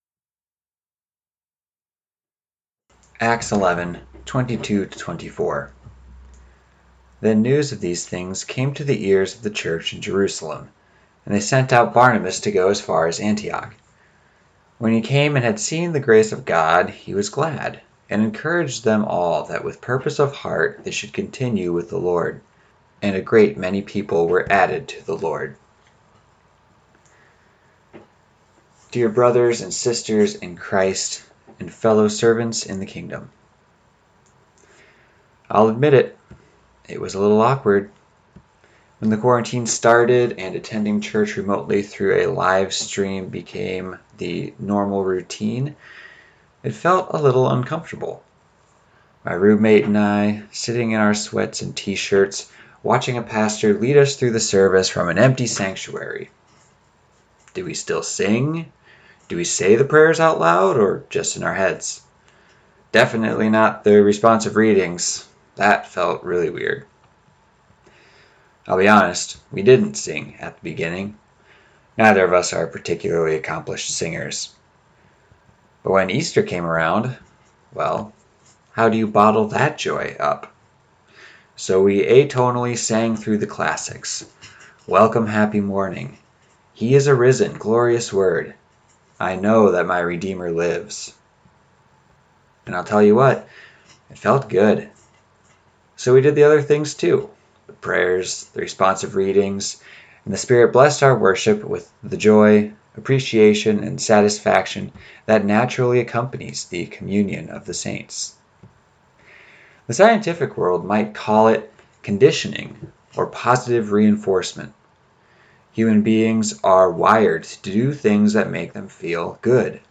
2020-05-11 ILC Chapel — God Makes Us Sons of…